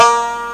BANJO.WAV